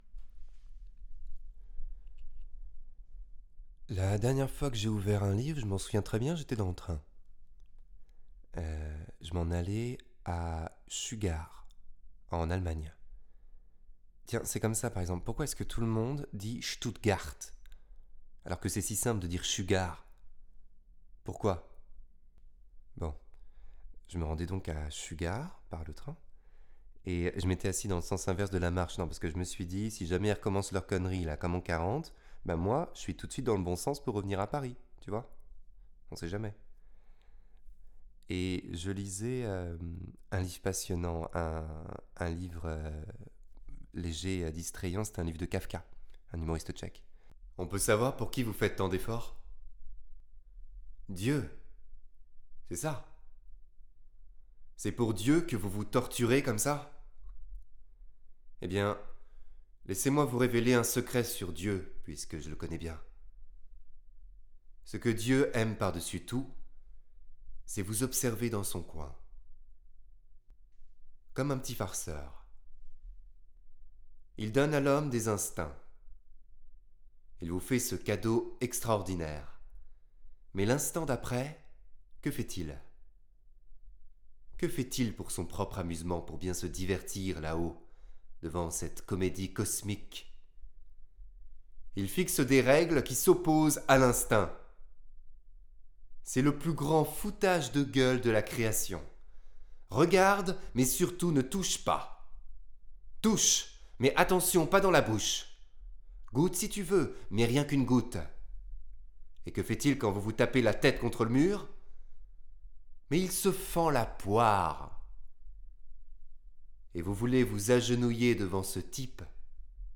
démo voix fiction
18 - 31 ans - Baryton-basse